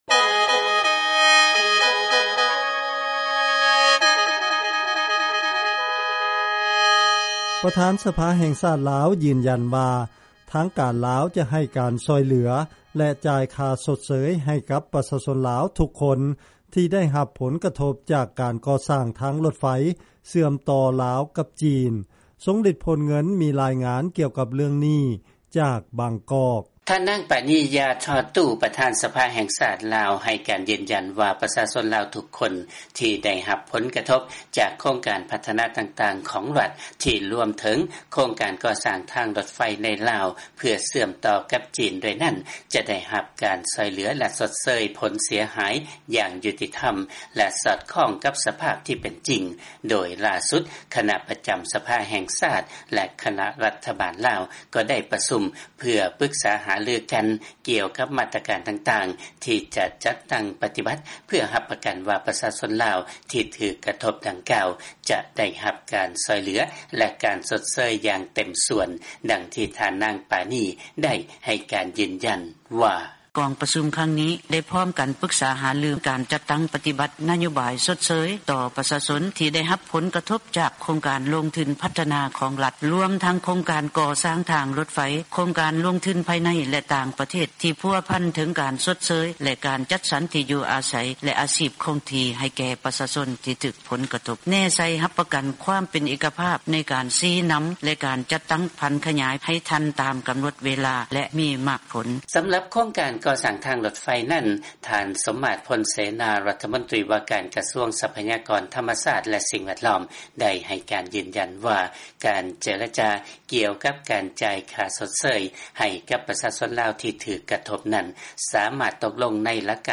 ຟັງລາຍງານ ປະທານ ສະພາແຫ່ງຊາດ ຢືນຢັນວ່າ ຈະຊ່ວຍເຫຼືອ ປະຊາຊົນ ທີ່ໄດ້ຮັບຜົນກະທົບ ຈາກການກໍ່ສ້າງທາງ ລາວ-ຈີນ.